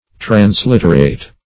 Transliterate \Trans*lit"er*ate\, v. t. [Pref. trans- + L.